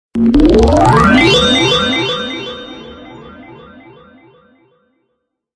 Electric Chimes - Botón de Efecto Sonoro